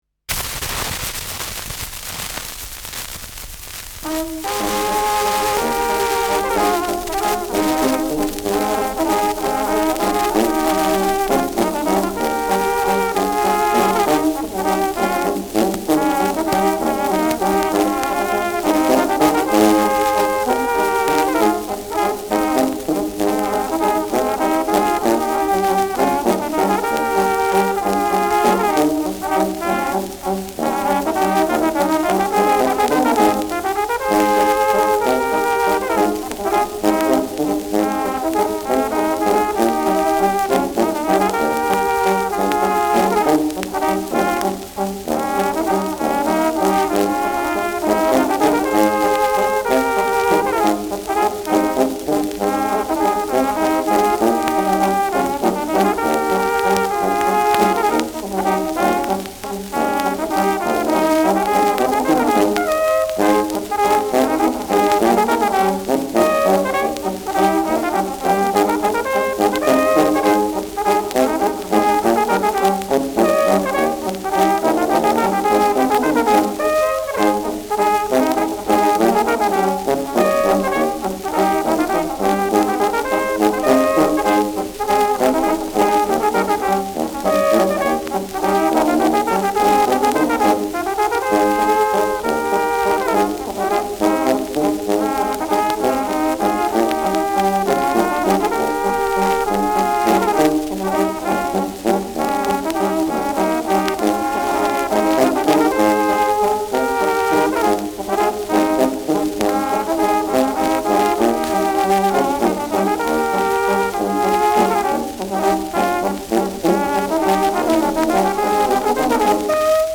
Schellackplatte
leichtes Rauschen : präsentes Knistern : „Schnarren“ : abgespielt : leiert : vereinzeltes Knacken
Pinzgauer Bauernkapelle (Interpretation)
[Wien] (Aufnahmeort)